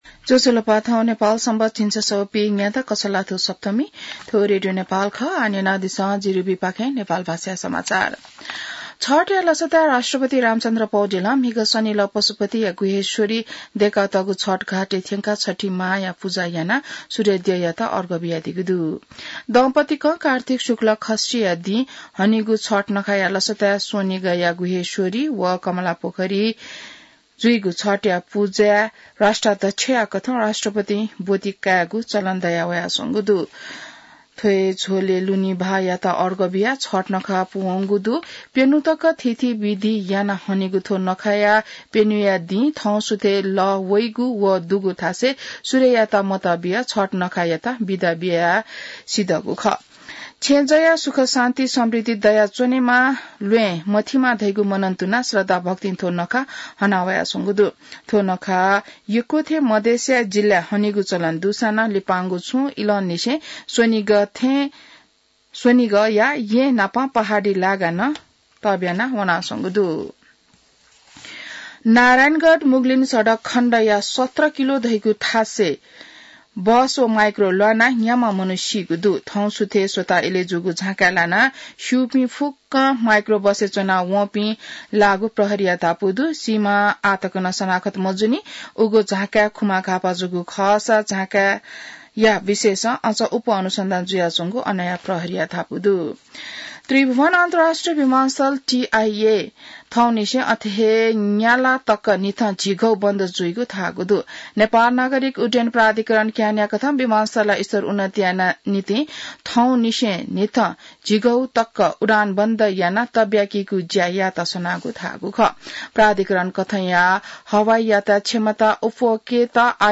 नेपाल भाषामा समाचार : २४ कार्तिक , २०८१